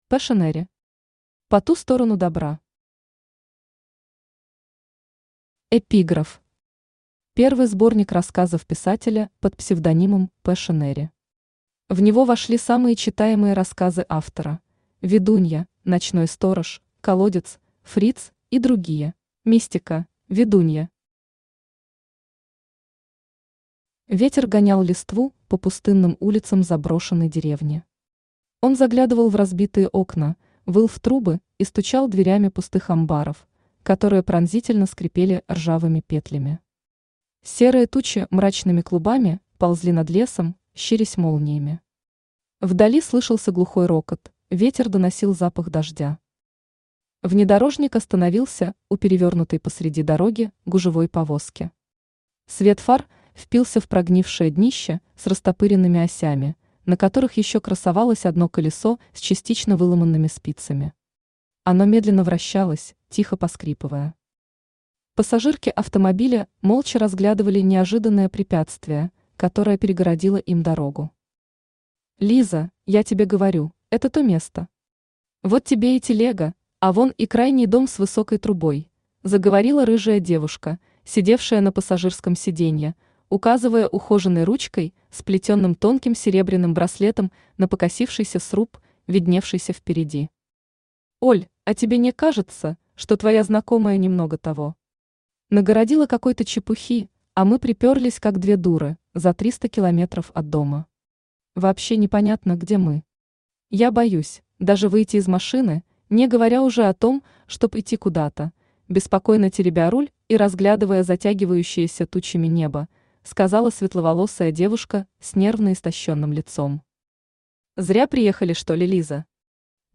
Аудиокнига По ту сторону добра | Библиотека аудиокниг
Aудиокнига По ту сторону добра Автор Passionary Читает аудиокнигу Авточтец ЛитРес.